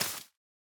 Minecraft Version Minecraft Version snapshot Latest Release | Latest Snapshot snapshot / assets / minecraft / sounds / block / moss / break1.ogg Compare With Compare With Latest Release | Latest Snapshot
break1.ogg